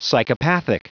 Prononciation du mot psychopathic en anglais (fichier audio)
Prononciation du mot : psychopathic